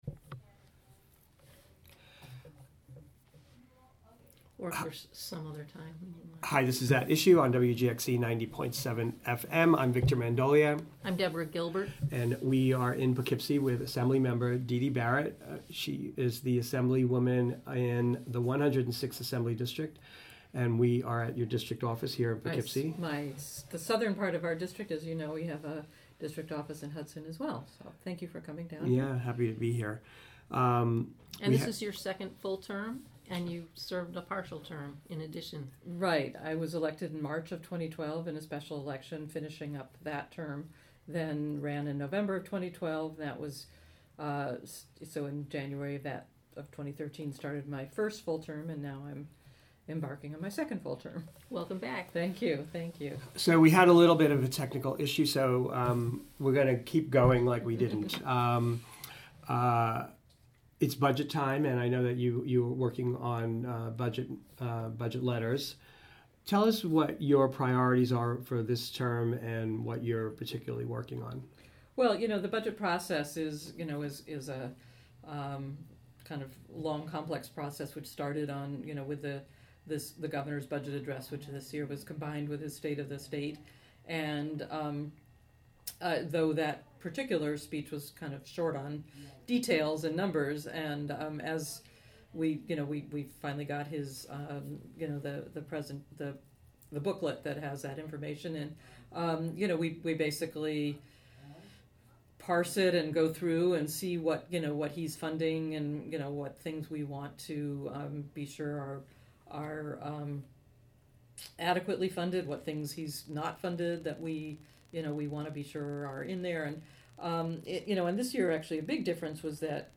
New York State Assembly member Didi Barrett discusses her legislative agenda for the coming year, and the 2015-16 state budget.
Interviewed